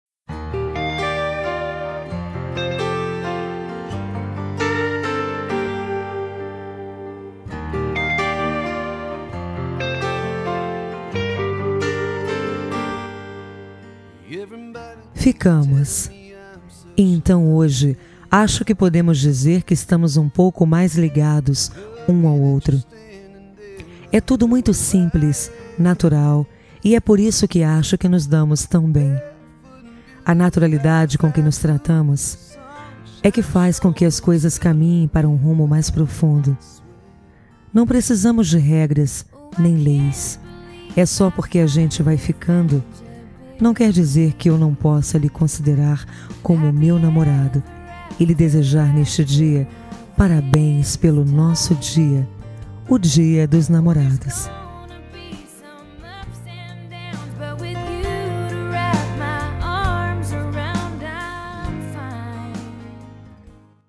Telemensagem Dia Dos Namorados Ficante
Voz Feminina